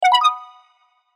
LINEなどのポップアップ通知、メッセージ通知なので丁度良いポップアップ音。